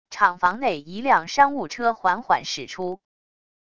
厂房内一辆商务车缓缓驶出wav音频